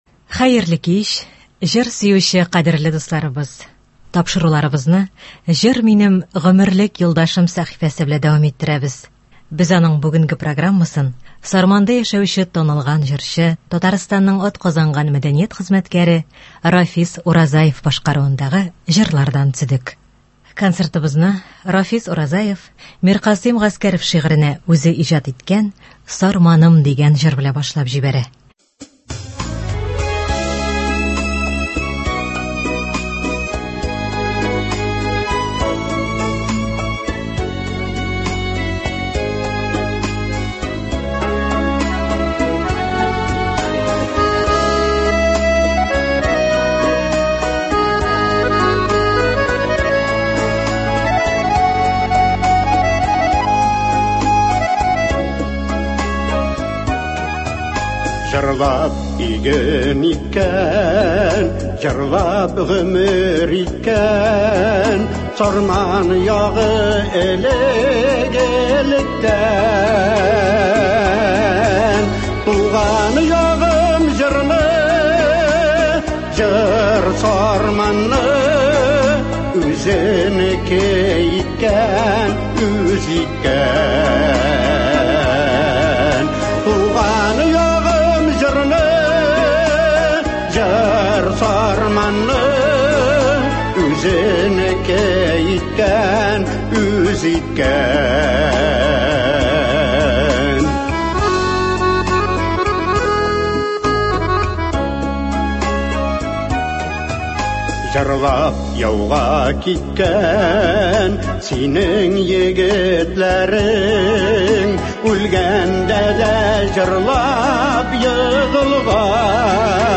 Үзешчән башкаручылар чыгышы.
Концерт (05.02.24)